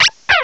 cry_not_gothita.aif